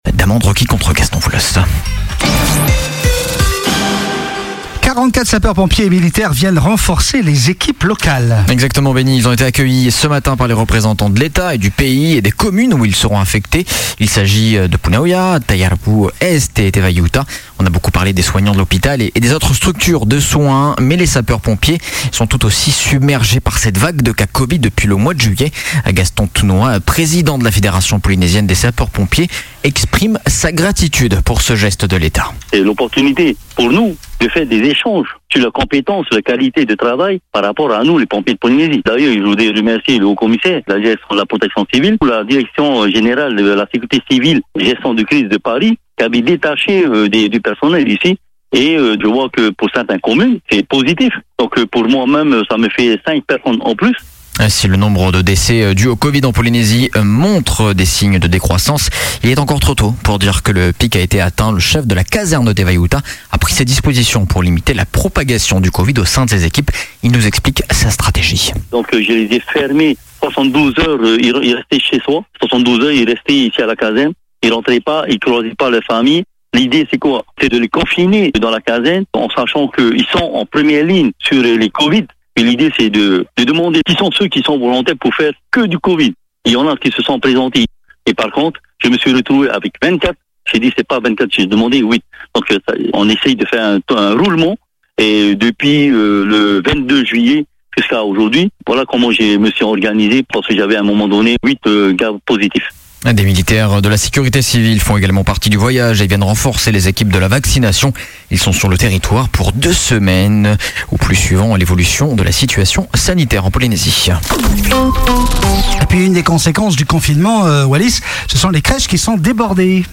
Journal de 12h00, le 06/09/21